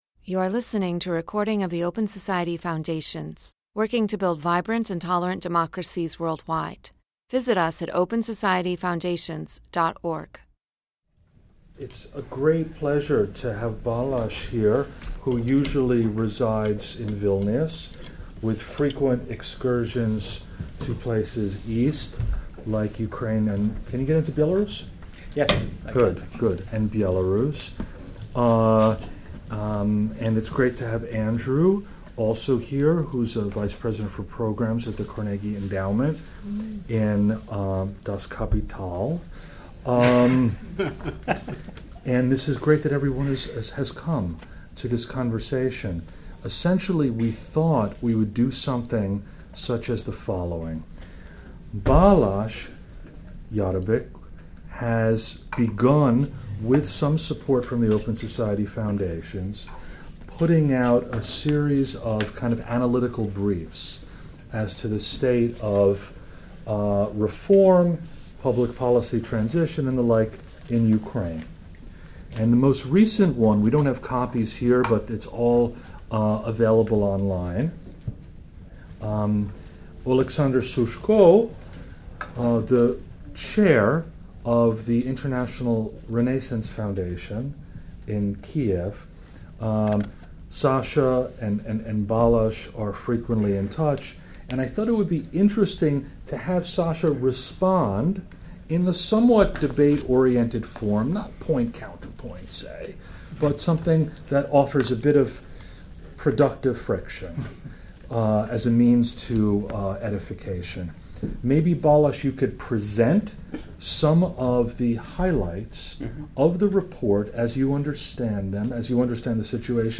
Quo Vadis: Ukraine? A Conversation with Two Leading Experts